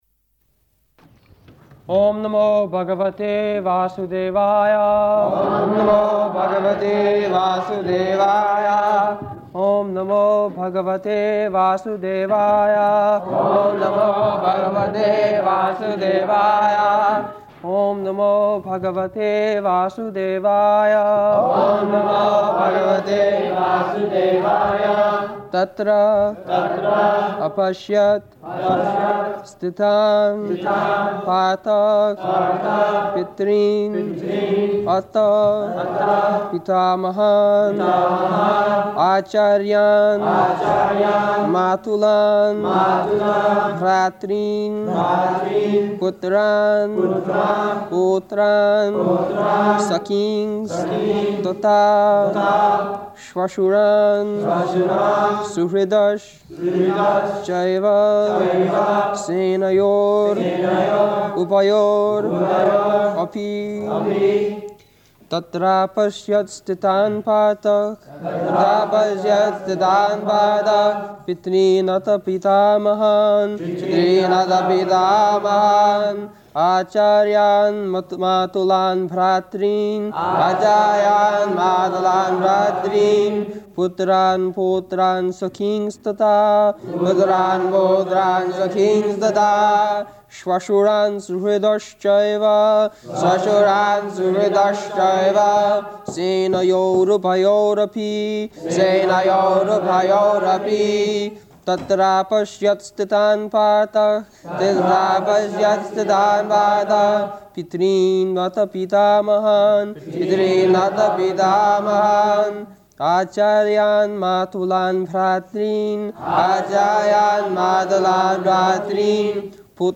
July 21st 1973 Location: London Audio file
[Prabhupāda and devotees repeat]